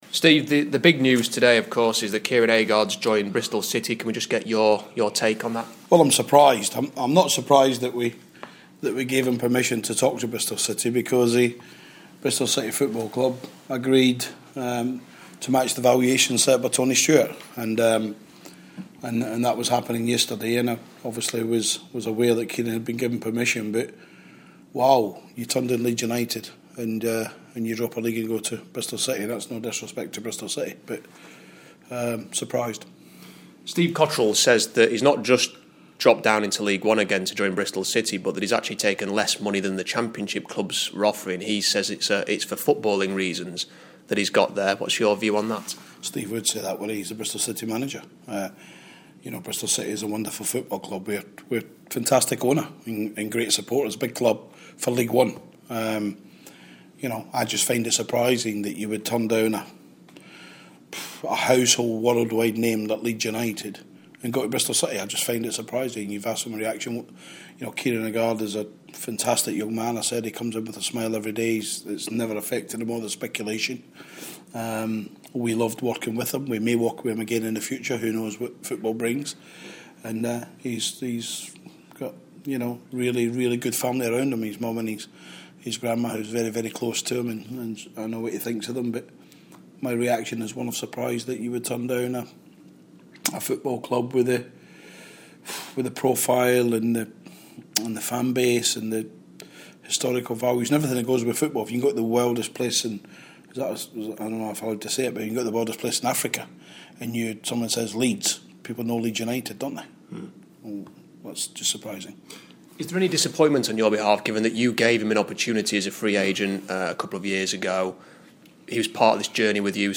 INTERVIEW: Rotherham Utd boss Steve Evans on Kieran Agard's move to Bristol City & pre-Millwall
The Millers manager speaking